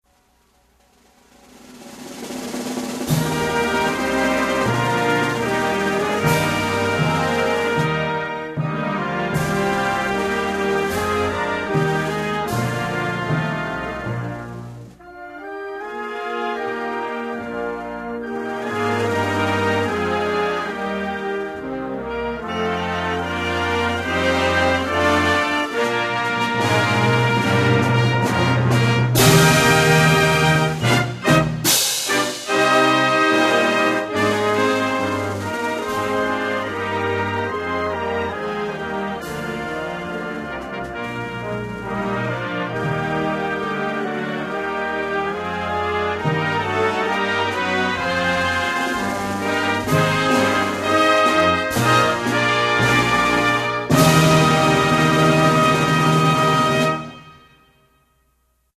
Motherland_(instrumental).mp3